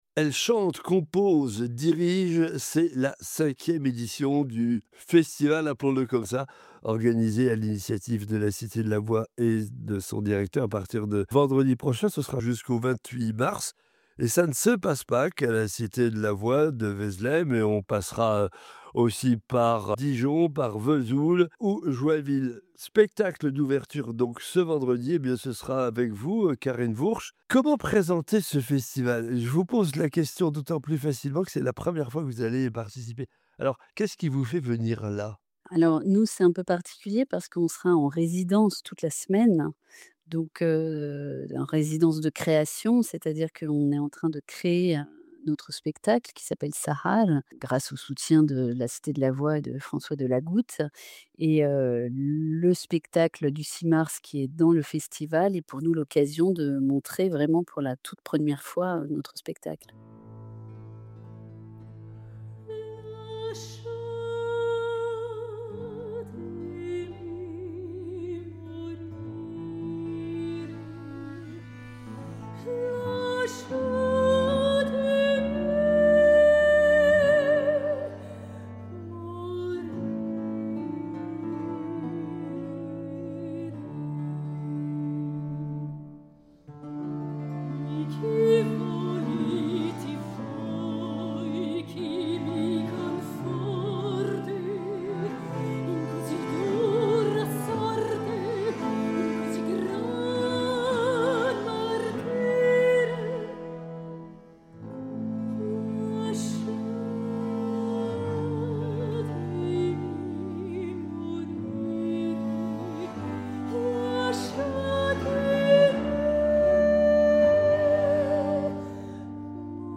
Echange avec la soprano lyrique